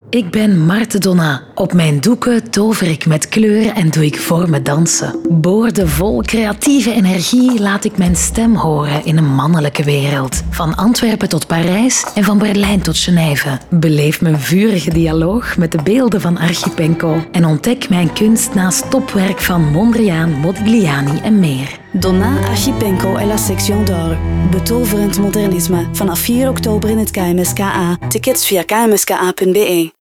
Profundo, Joven, Travieso, Versátil, Cálida